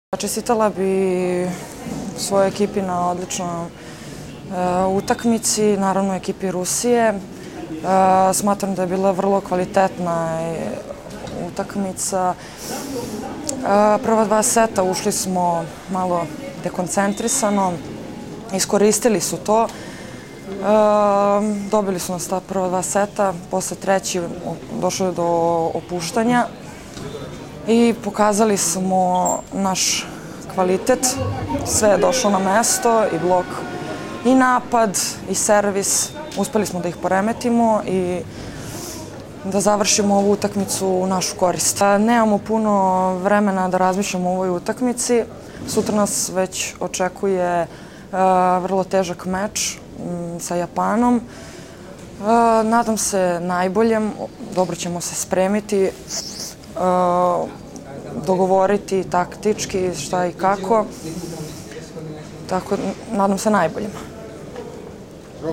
IZJAVA JOVANE STEVANOVIĆ